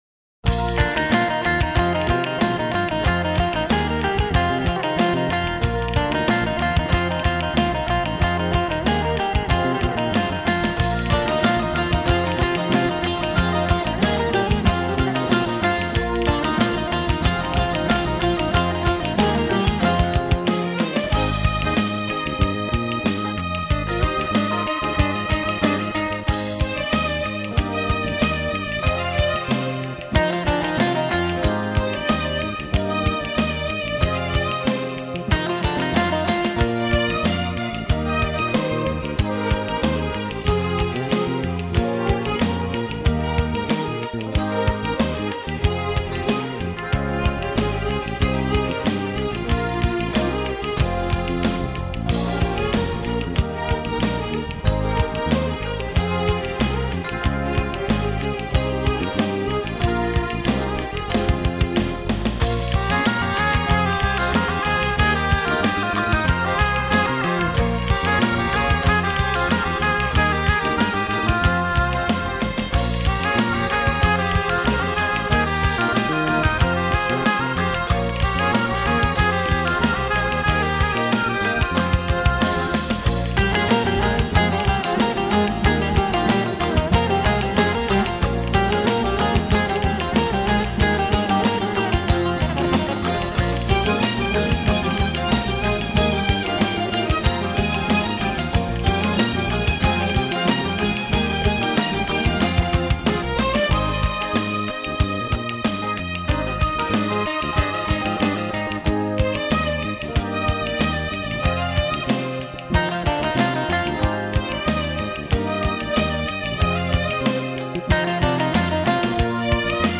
آهنگ لایت زیبا وشنیدنی
[نوع آهنگ: لایت]